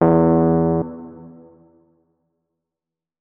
electric_piano
notes-16.ogg